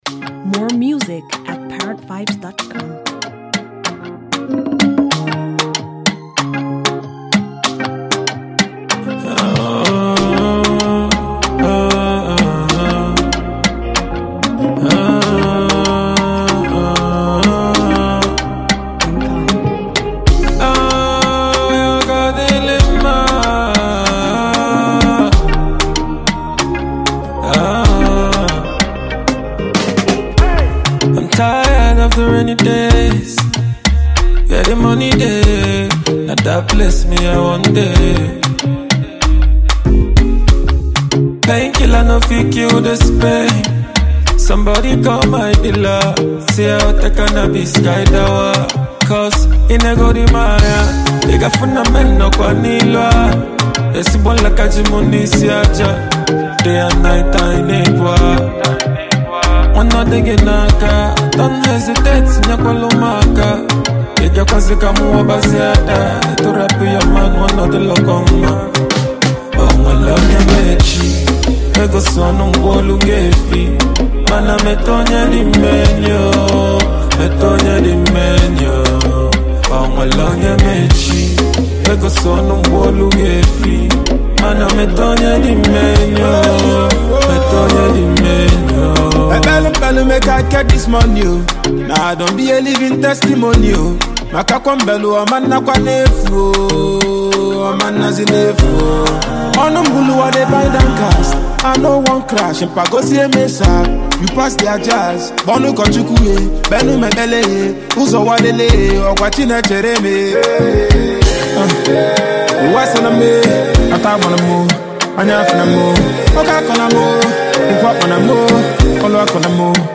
Super gifted Nigerian singer and songwriter from the East
hitmaker & award-winning Nigerian rapper